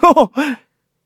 Kibera-Vox-Laugh_kr.wav